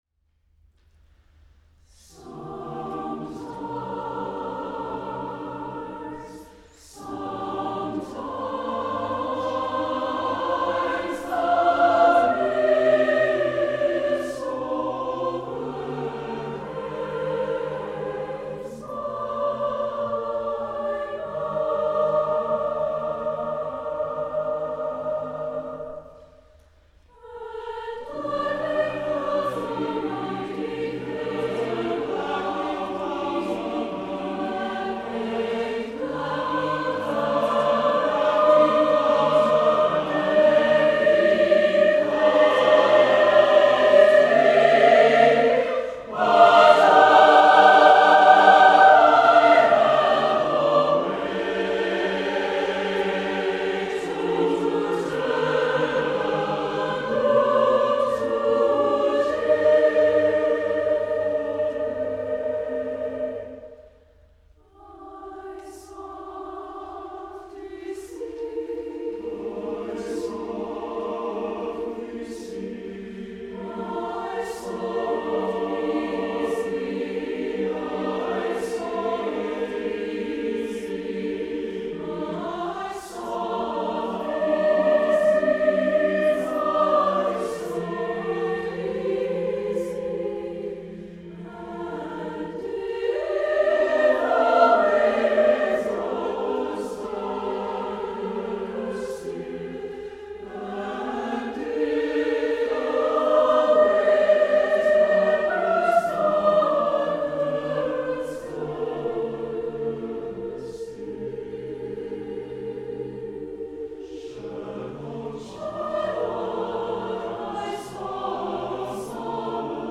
Voicing: "SATB divisi"